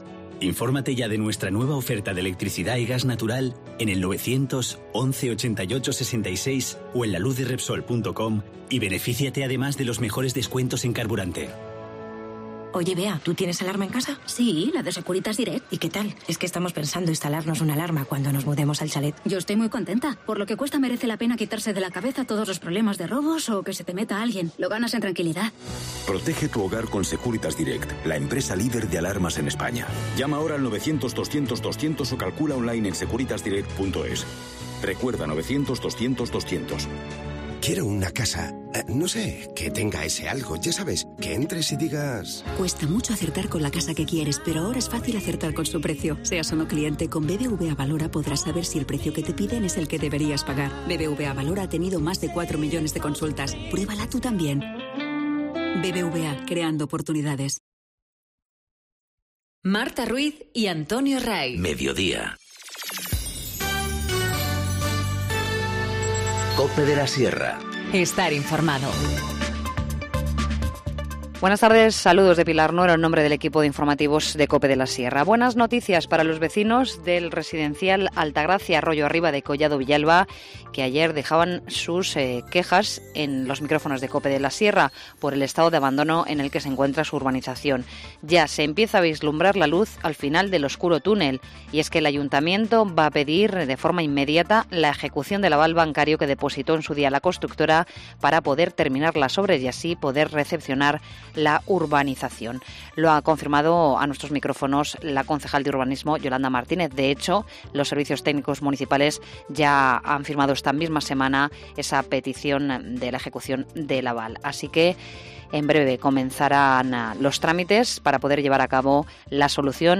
Informativo Mediodía 28 marzo 14:20h